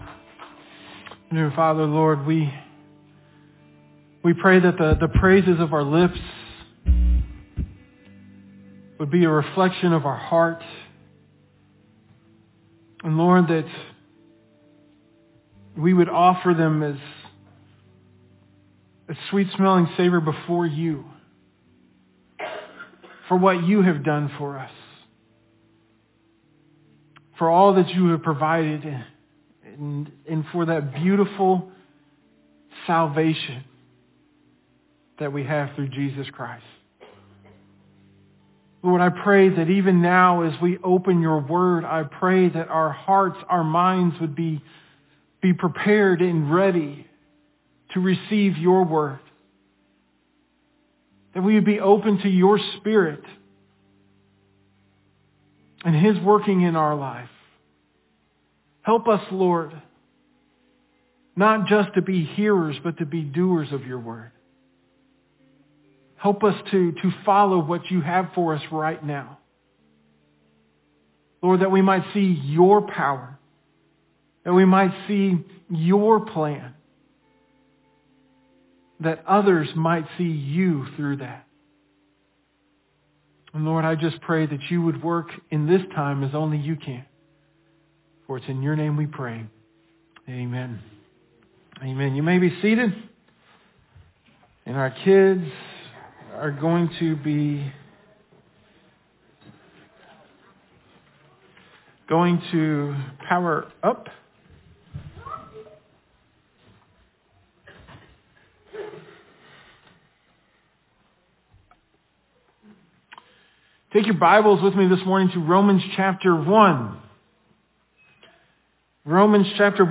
Most Recent Sermon Audio